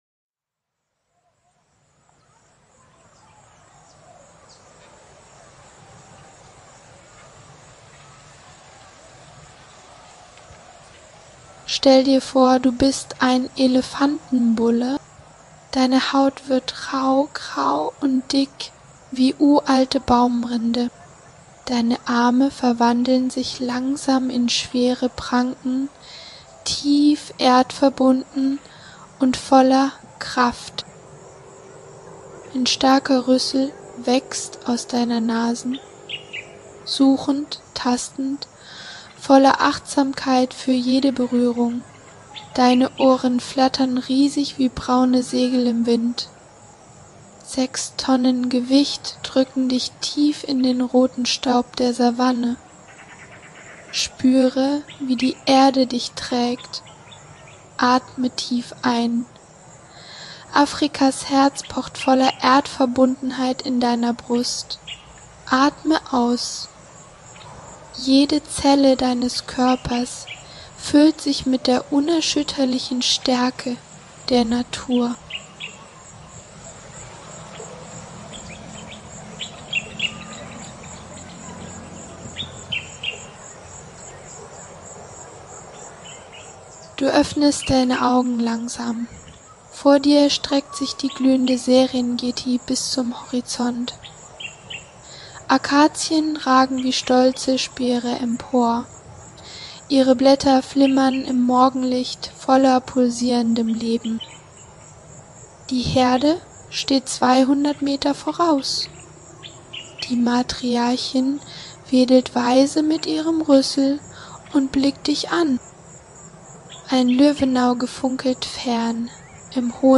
Diese 40-minütige geführte Meditation schenkt dir tiefe Achtsamkeit, pure Lebensfreude und bedingungslose Liebe zur wilden Natur.